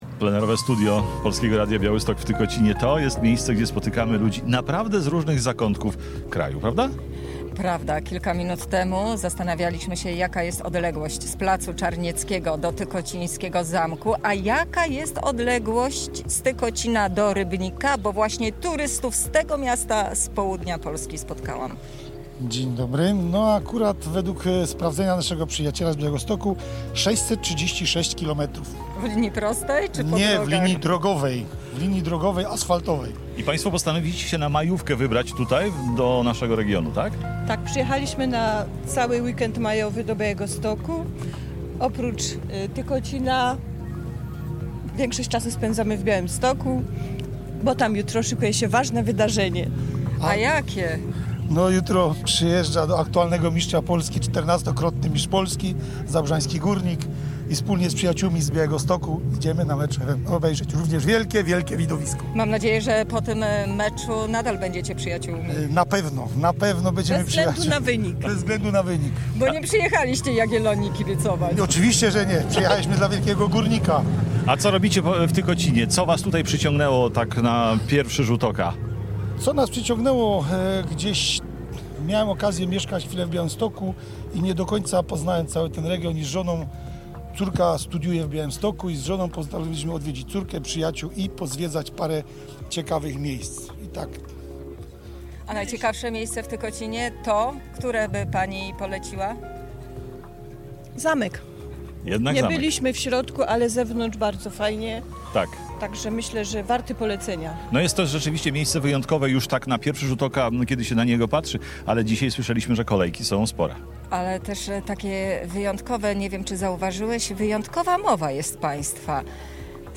W sobotę, 3 maja właśnie z tego miasteczka program nadawało Polskie Radio Białystok.
Nasz program z Tykocina nadawaliśmy od 14:00 do 18.00. W naszym plenerowym studiu na Placu Czarnieckiego nasi słuchacze mogli podpatrzeć pracę dziennikarzy, ale także wziąć udział w wielu konkursach, które przygotowaliśmy.